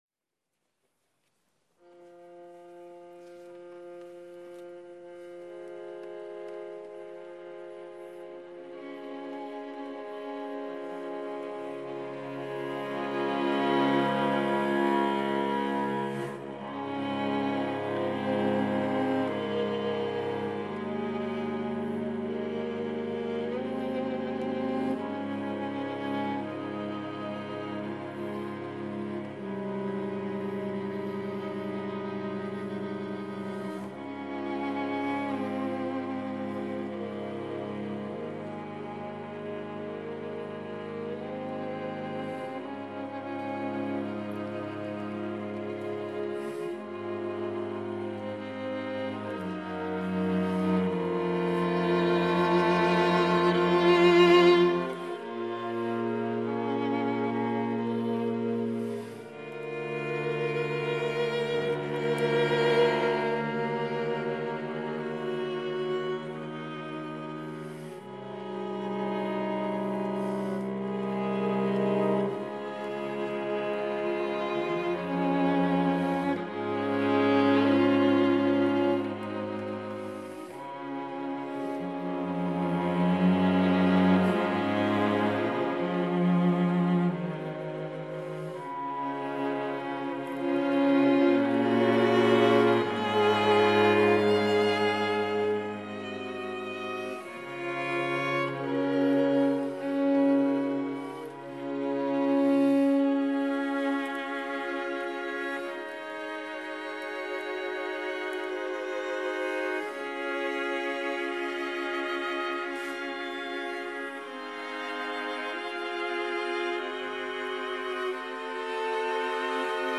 Lento assai, cantante e tranquillo
Kreutzer Quartet
Live at Robinson College Cambridge
Lento-assai-cantante-e-tranquillo-in-D-flat-major.mp3